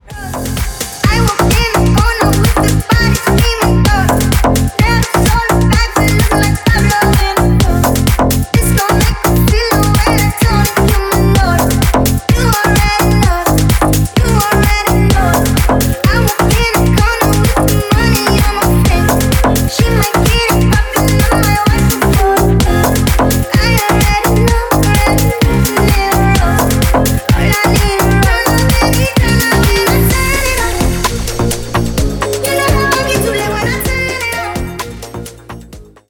бесплатный рингтон в виде самого яркого фрагмента из песни
Ремикс # Поп Музыка
тихие